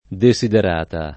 desiderata [lat.